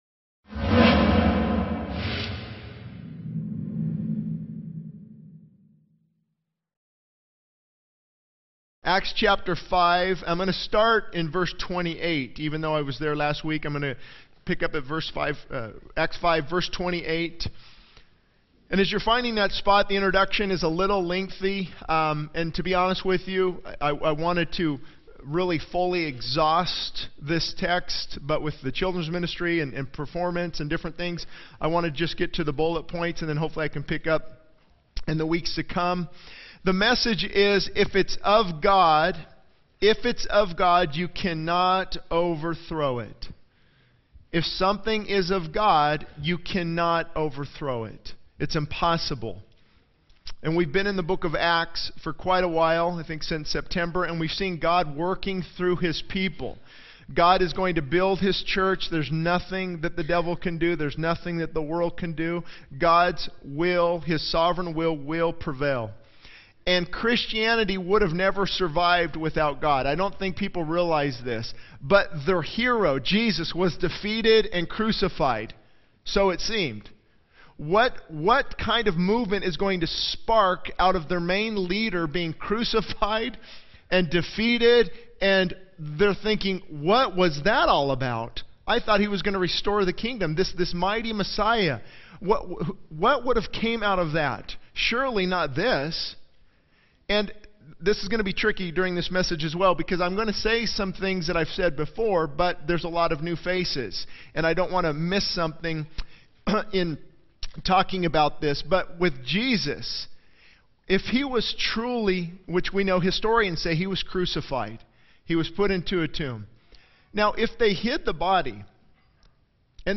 Ultimately, he reminds the congregation that resisting God is futile, and they must choose to embrace His love and truth.